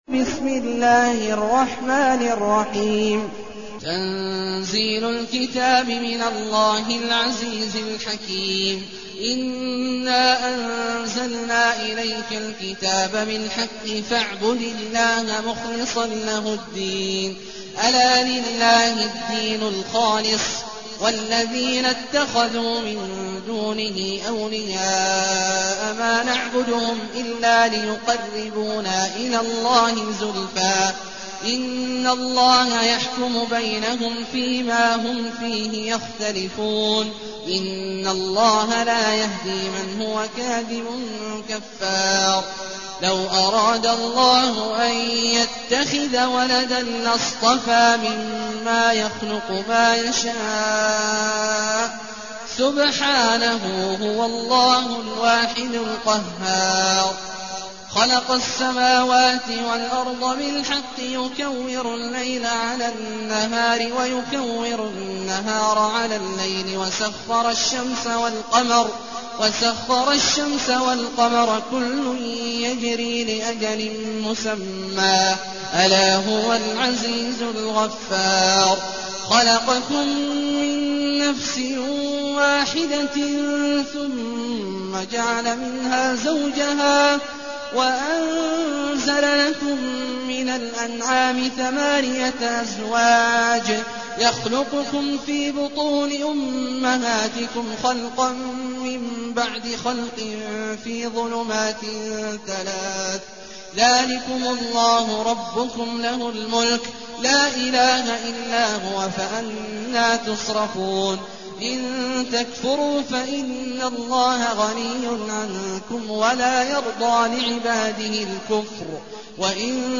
المكان: المسجد النبوي الشيخ: فضيلة الشيخ عبدالله الجهني فضيلة الشيخ عبدالله الجهني الزمر